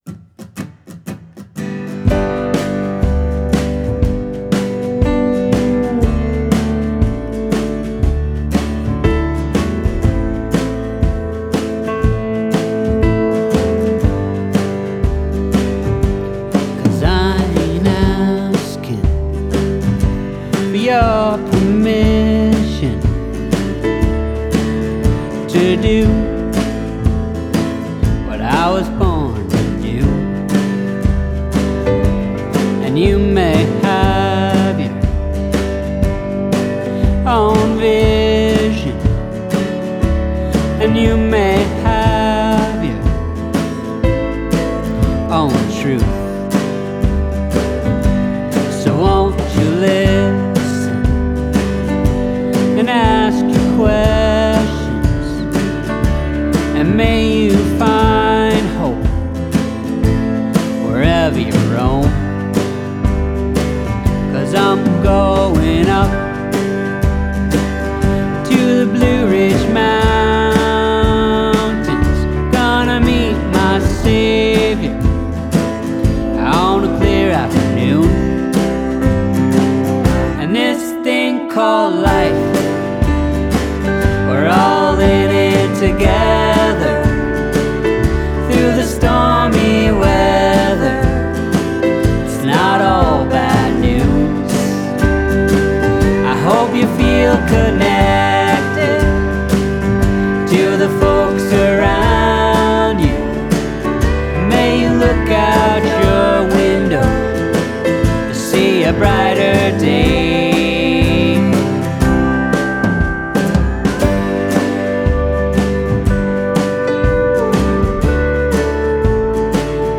21st Century Folk Rock!